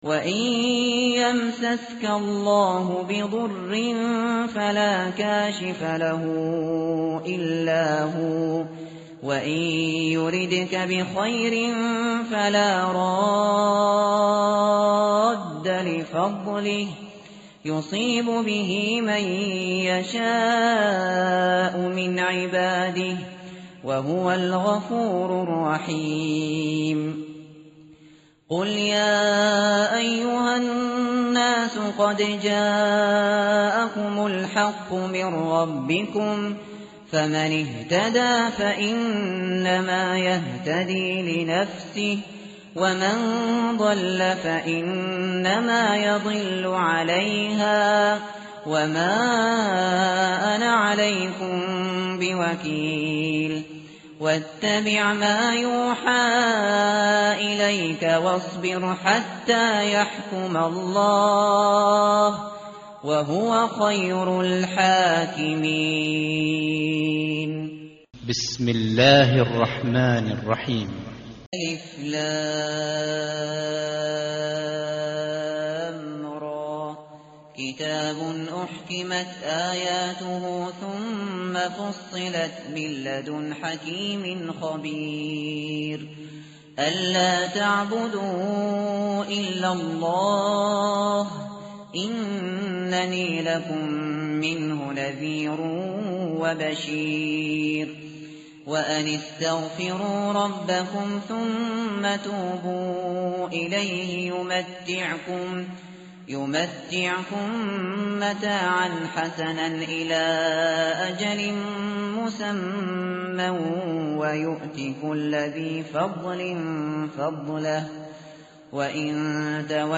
tartil_shateri_page_221.mp3